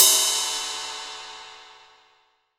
Index of /90_sSampleCDs/AKAI S6000 CD-ROM - Volume 3/Ride_Cymbal1/18INCH_ZIL_RIDE